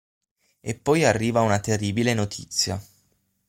Read more (feminine) item of news (feminine) piece of information Frequency A2 Hyphenated as no‧tì‧zia Pronounced as (IPA) /noˈtit.t͡sja/ Etymology From Latin nōtitia.